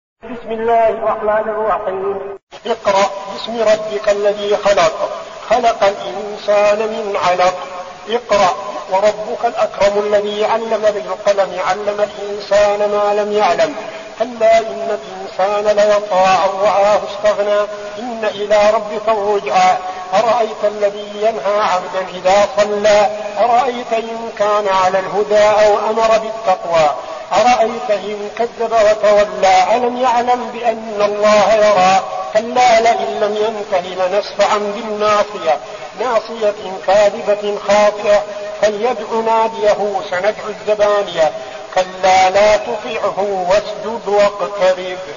المكان: المسجد النبوي الشيخ: فضيلة الشيخ عبدالعزيز بن صالح فضيلة الشيخ عبدالعزيز بن صالح العلق